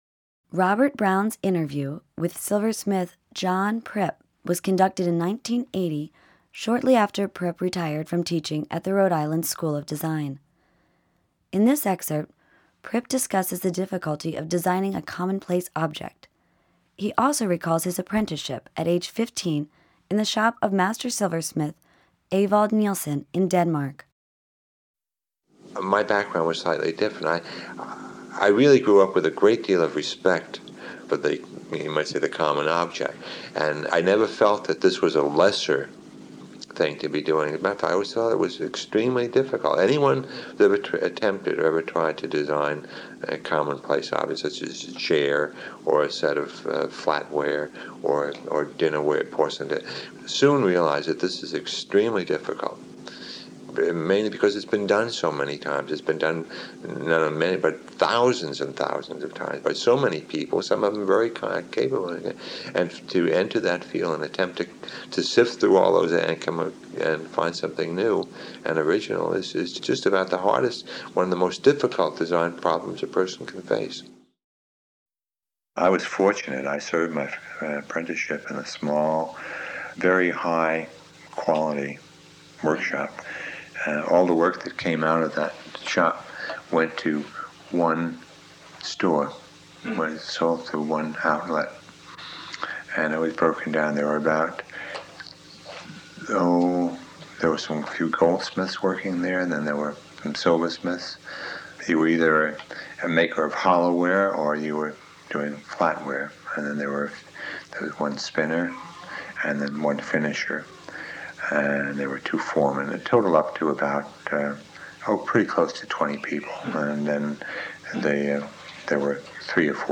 Oral history interview with